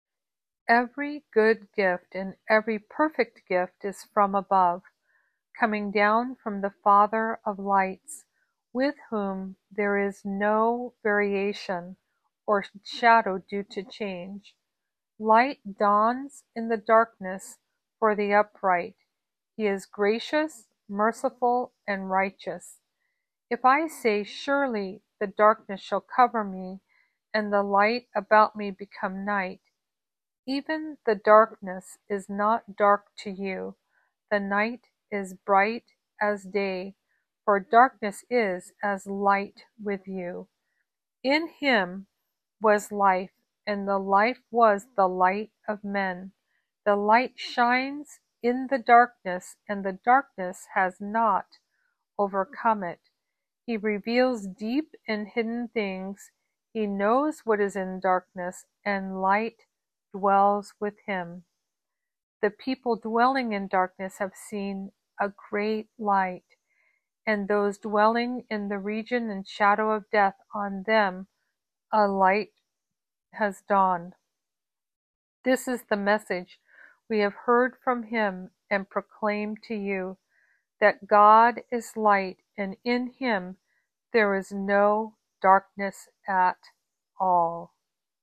Today I’m sharing an audio reading of a handpicked selection of scriptures on this theme ‘God is light’.